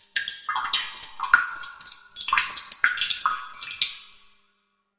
Cave Dripping
Echoing water drops falling in a dark cave with natural reverb and mineral resonance
cave-dripping.mp3